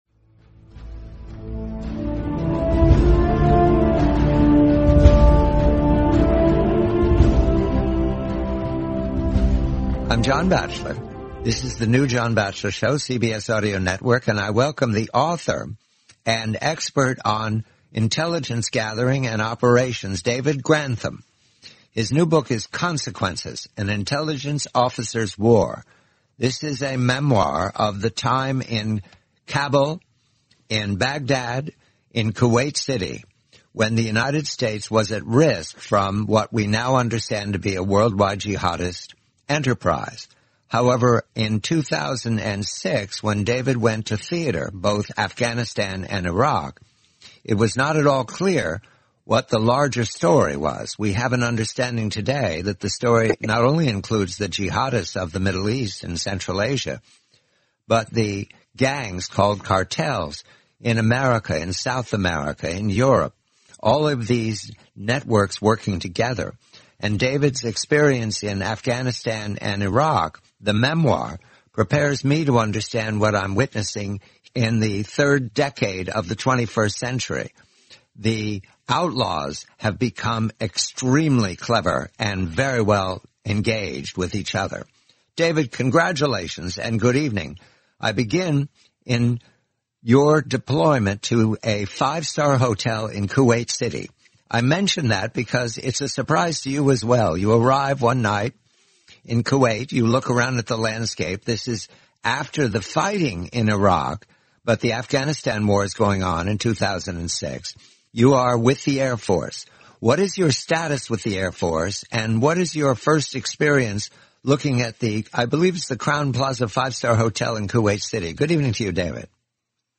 The complete, forty-minute interview, April 5, 2021.